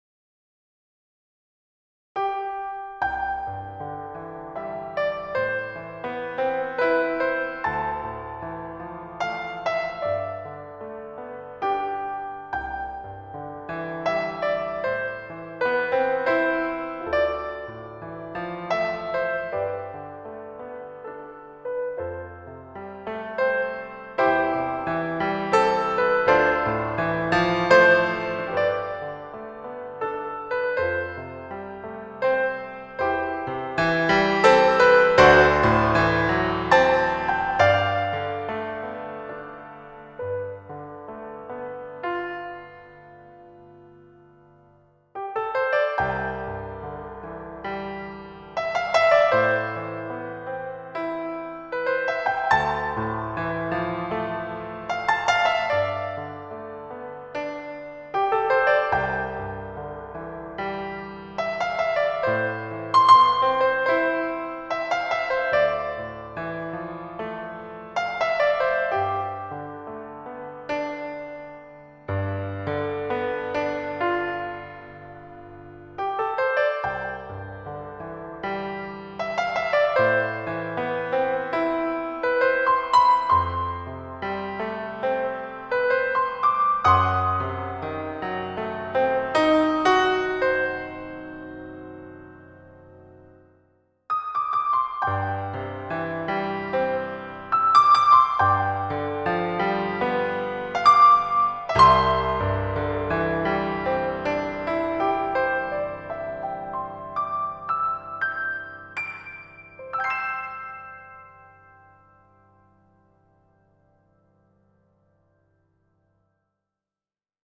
The melody leaps with large intervals, like:
These upward leaps create a soaring, uplifting feeling.